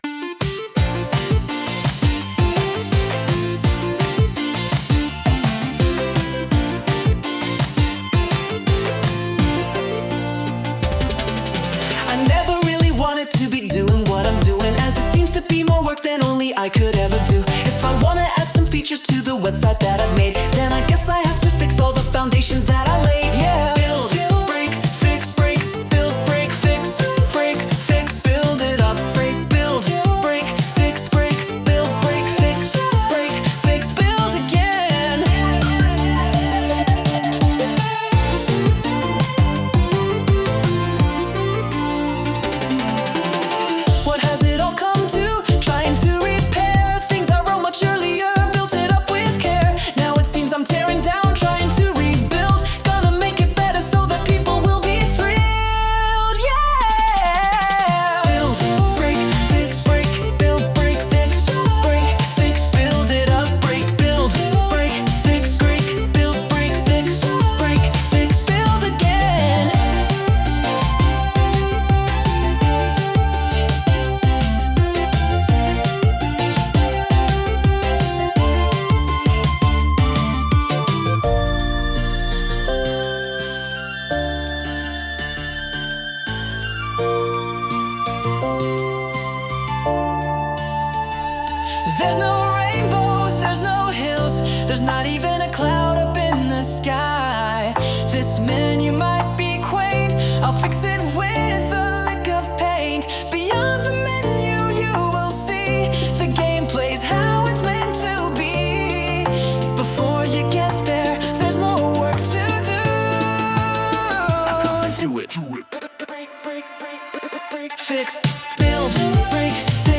Lyrics : By me
Sung by Suno